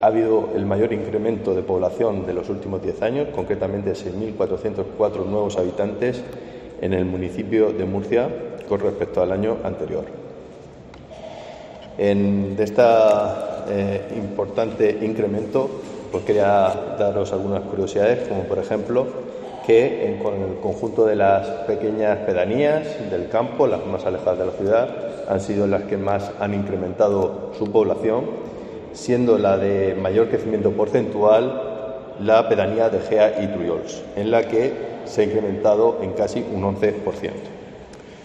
Marco Antonio Fernández, concejal de Pedanías y Vertebración Territorial